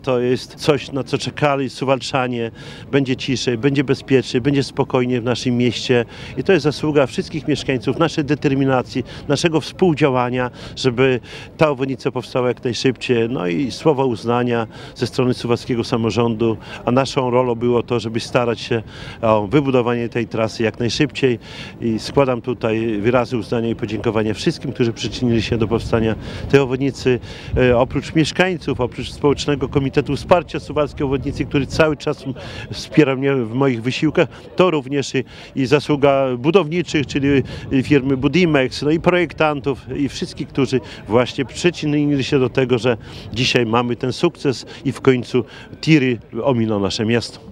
Obecny na uroczystości Czesław Renkiewicz, prezydent Suwałk w rozmowie z Radiem 5 nie krył radości z powstania obwodnicy. Przypomniał zaangażowanie mieszkańców w starania o budowę drogi, między innymi działania Społecznego Komitetu Wsparcia Budowy Obwodnicy Suwałk, który organizował protesty w tej sprawie.